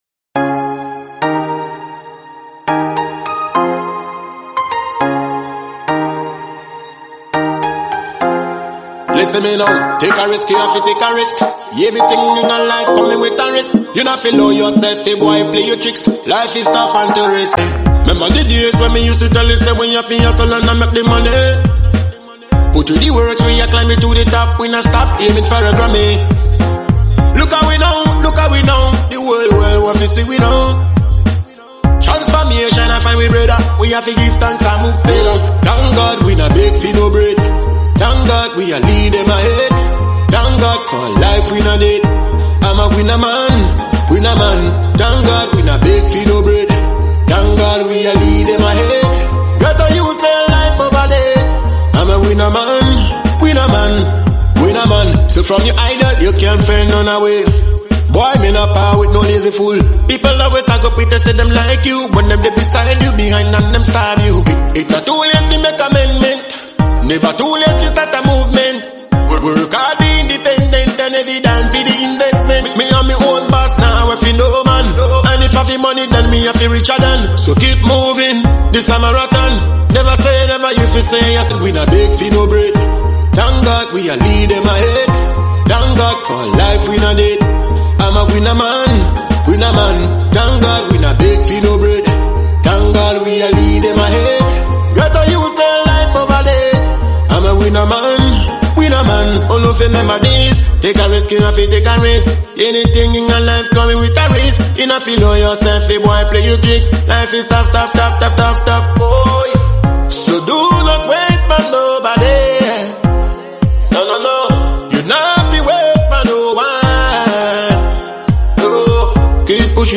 dancehall song